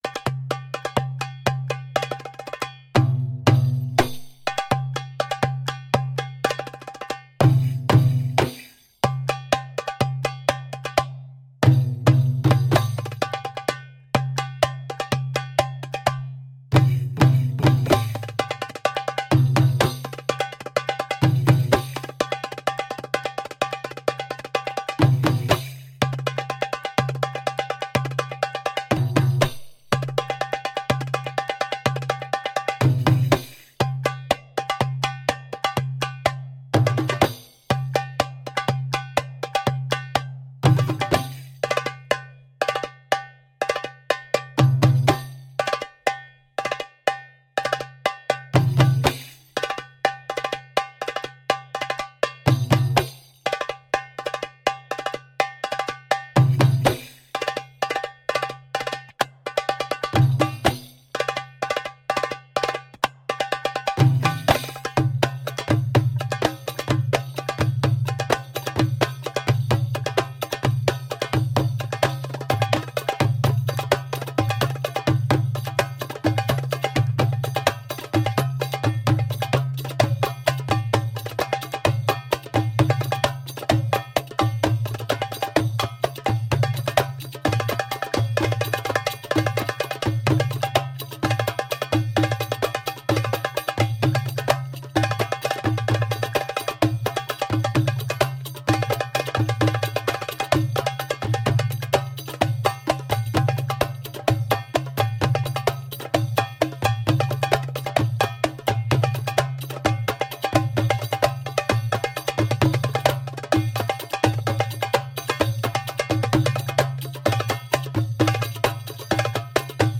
Sultry and percussive mid-east fusion.